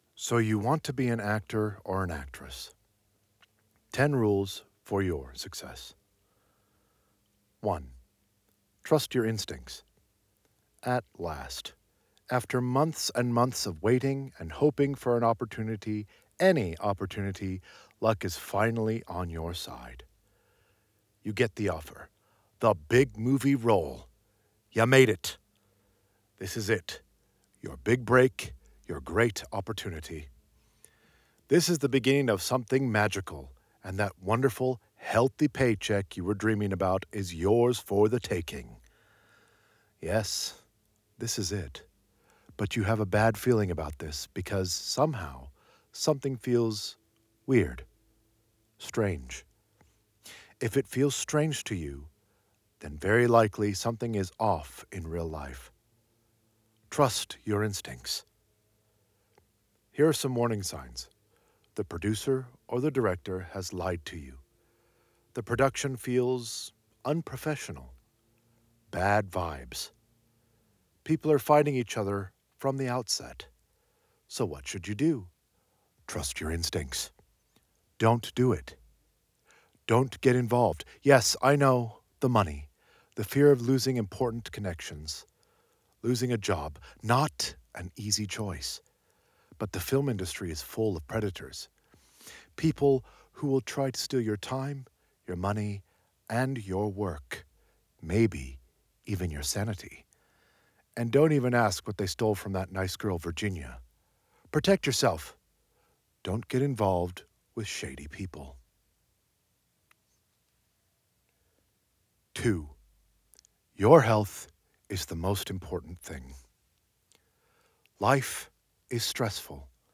His unique voice. He will be narrating texts and stories for us in the future.